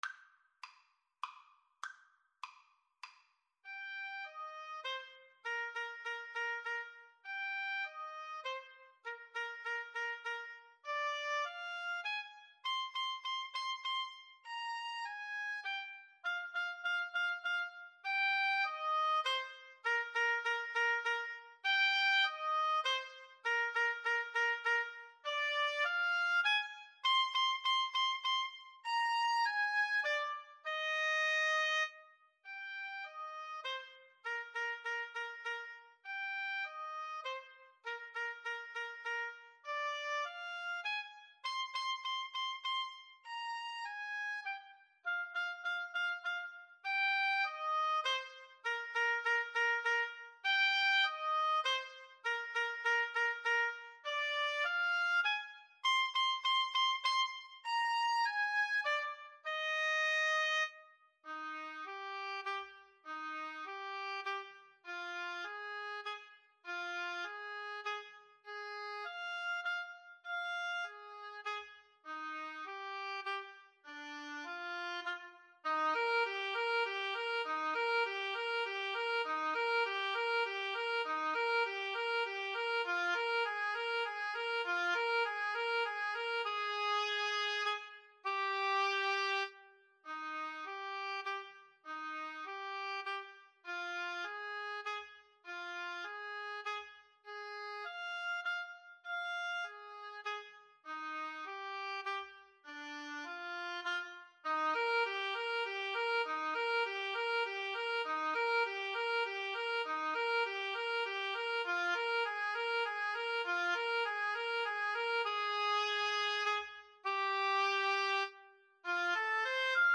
Free Sheet music for Oboe Duet
Eb major (Sounding Pitch) (View more Eb major Music for Oboe Duet )
3/4 (View more 3/4 Music)